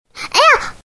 女孩哎呀一声音效免费音频素材下载